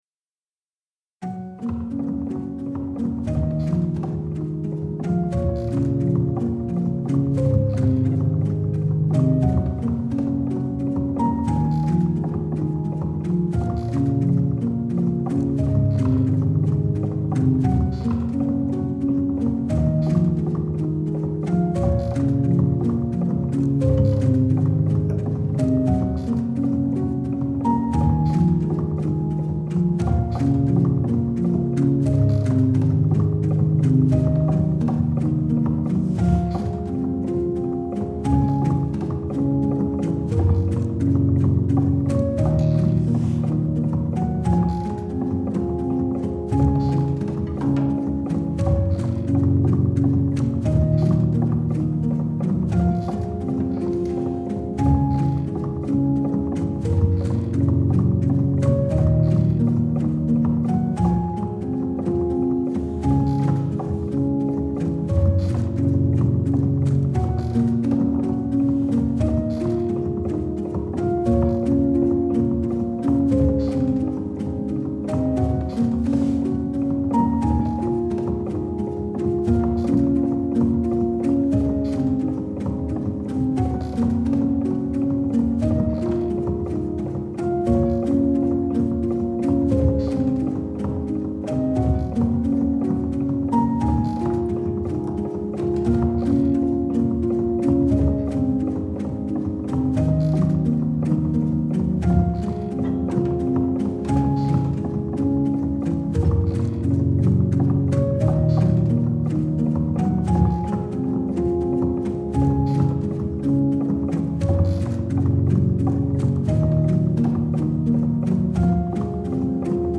Classical Crossover
پیانو آرامبخش موسیقی بی کلام آرامبخش